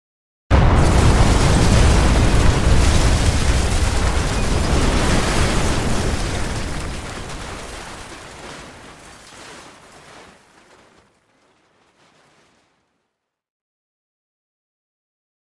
Building Explosion